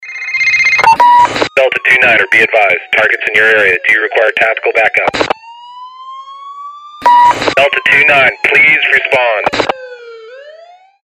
Tono de llamada Radio De La Policía
Categoría Alarmas